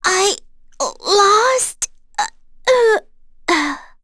Erze-Vox_Dead.wav